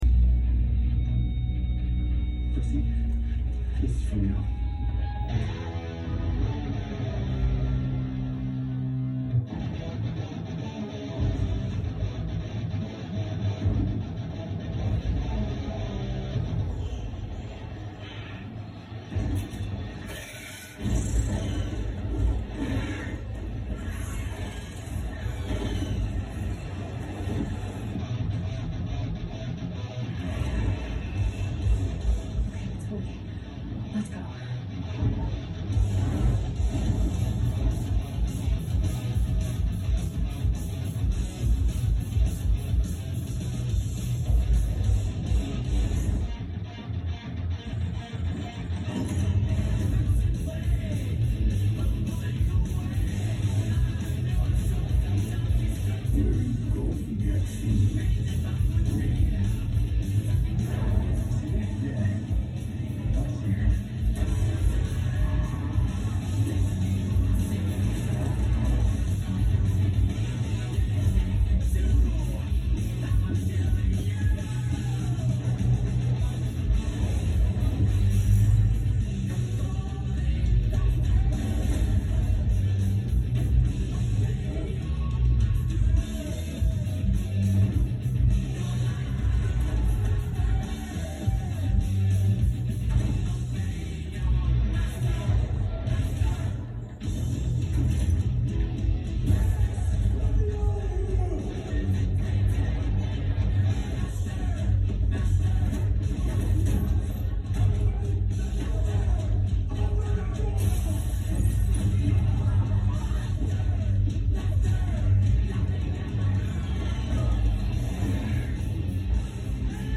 guitar scene